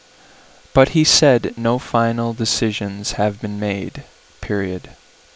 Bandlimited to telephone bandwidth, expanded to 8Khz
Fullband Signal Bandlimited signal Reconstructed signal